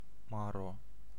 Ääntäminen
IPA : /siː/ US : IPA : [siː] UK